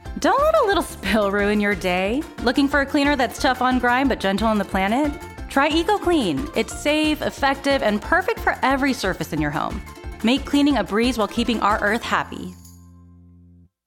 expressive female voice talent